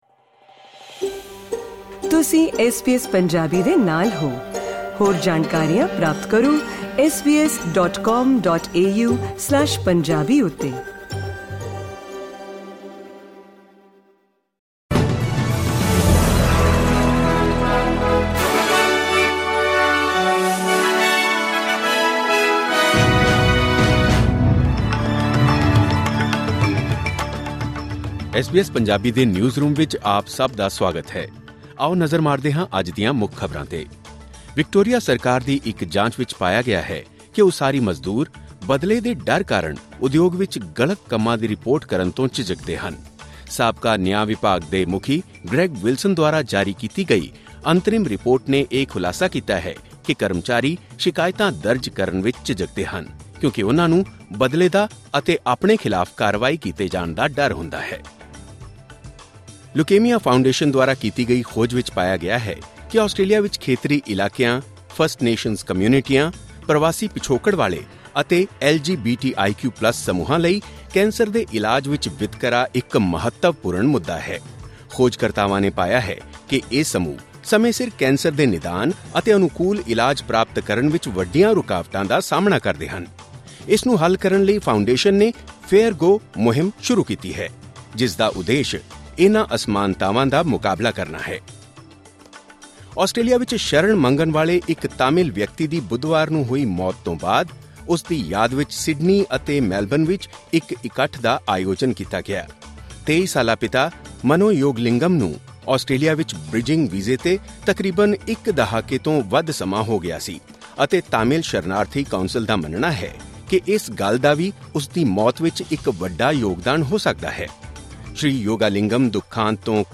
ਐਸ ਬੀ ਐਸ ਪੰਜਾਬੀ ਤੋਂ ਆਸਟ੍ਰੇਲੀਆ ਦੀਆਂ ਮੁੱਖ ਖ਼ਬਰਾਂ: 30 ਅਗਸਤ 2024